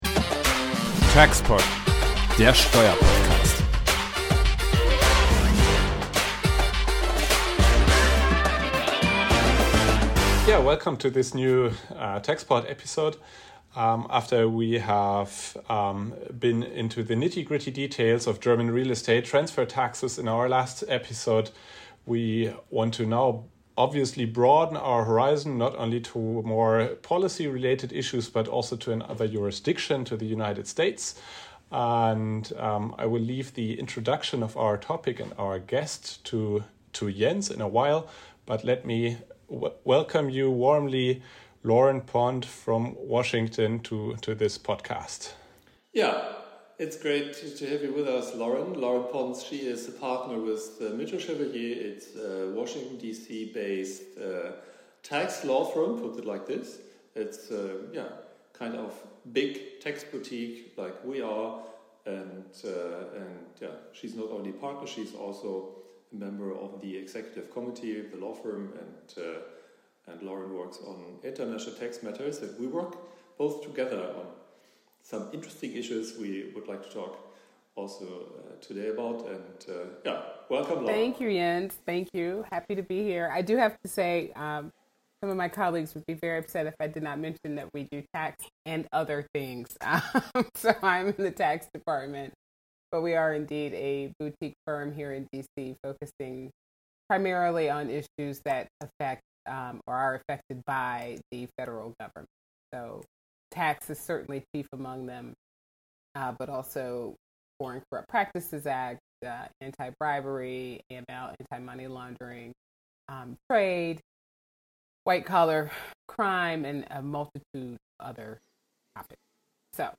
Teils zu zweit, teils gemeinsam mit spannenden und hochkarätigen Gästen diskutieren die beiden regelmäßig wieder die volle Bandreite hochaktueller Themen rund um das Internationale Steuerrecht.